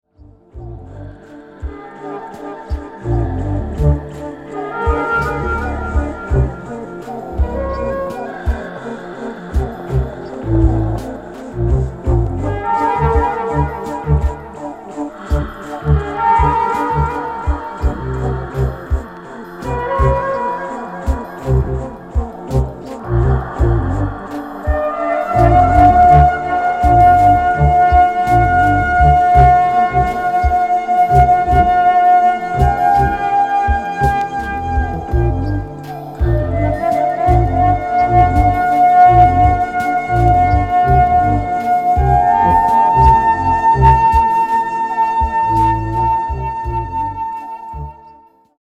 フルート、声、リズムマシーンの他、鳥の鳴き声などの自然音を交えた多重録音による作品。
キーワード：ミニマル　即興　フルート